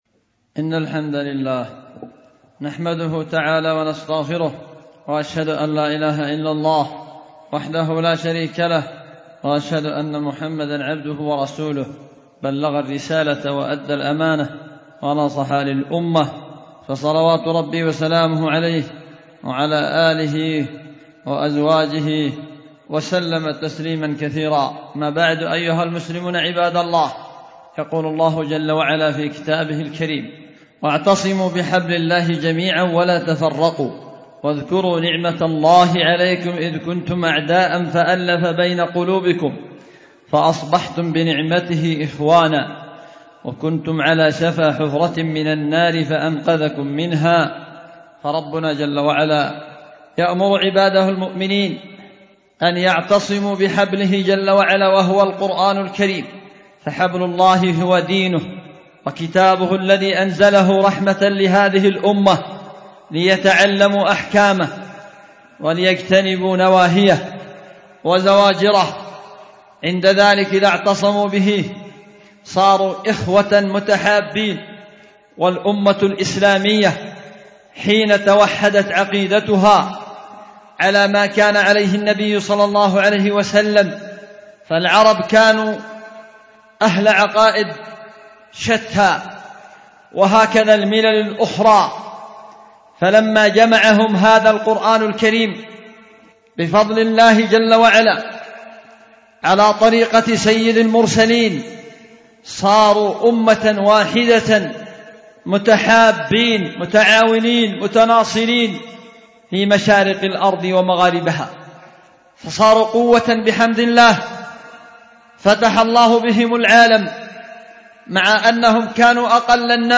خطبة
في دار الحديث بمسجد السلف الصالح بذمار